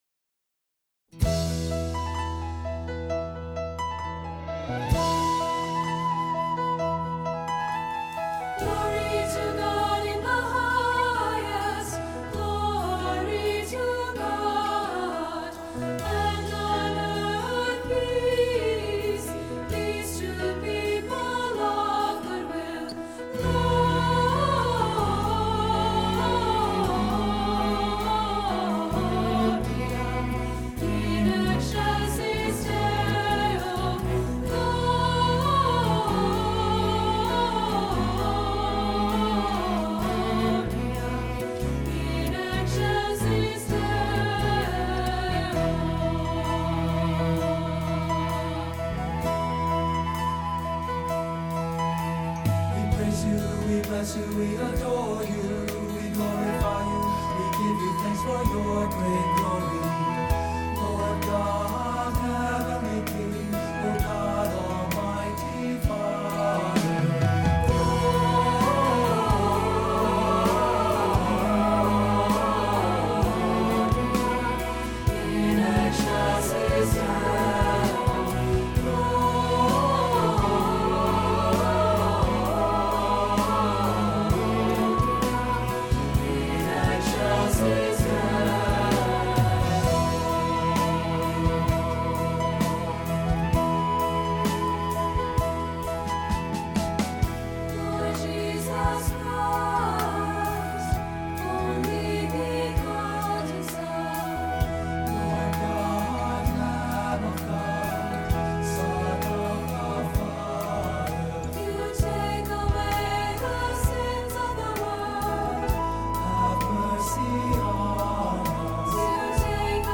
Accompaniment:      Keyboard;Piano and Organ
Music Category:      Choral